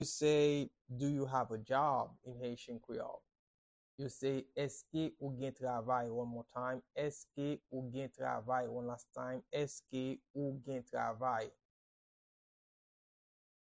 Pronunciation and Transcript:
Do-you-have-a-job-in-Haitian-Creole-–-Eske-ou-gen-travay-pronounciation-by-a-Haitian-teacher.mp3